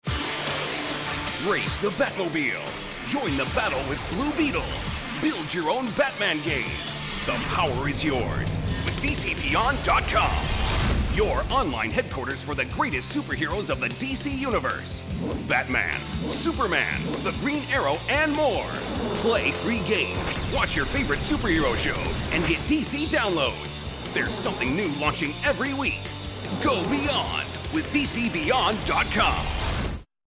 All of our contracted Talent have broadcast quality home recording studios.